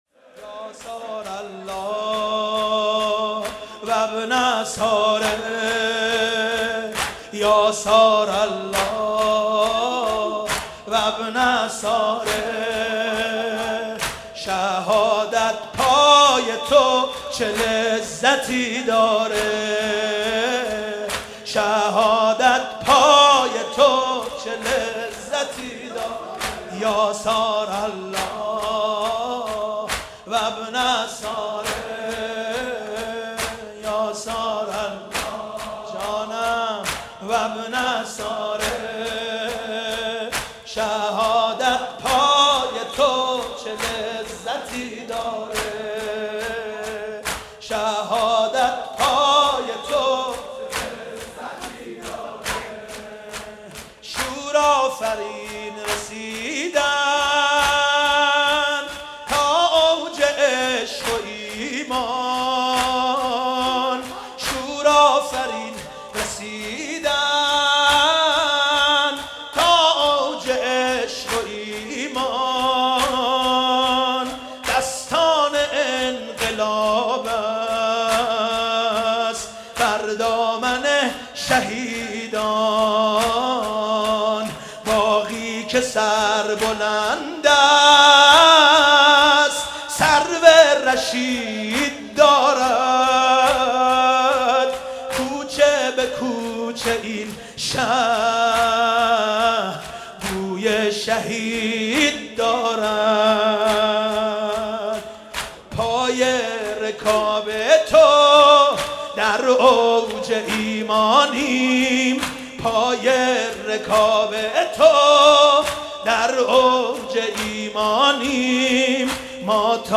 شور ، سال 94،جدید